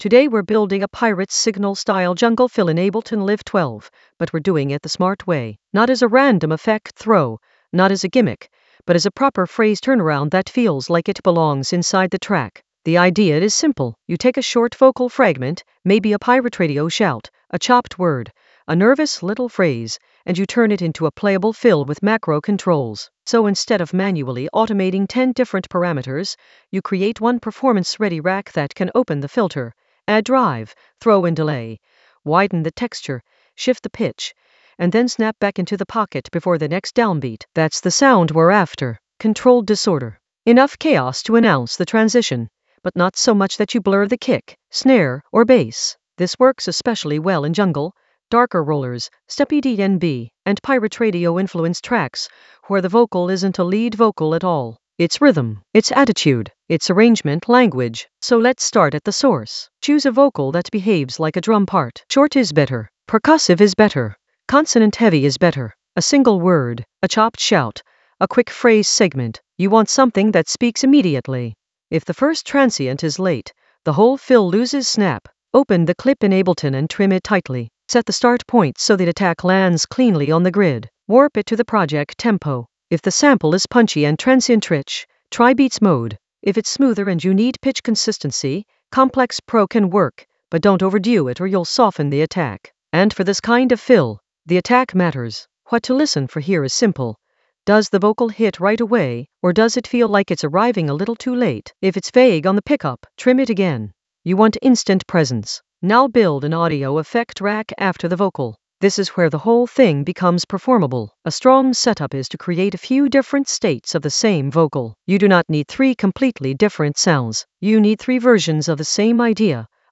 An AI-generated advanced Ableton lesson focused on Pirate Signal Ableton Live 12 a jungle fill blueprint using macro controls creatively in the Vocals area of drum and bass production.
Narrated lesson audio
The voice track includes the tutorial plus extra teacher commentary.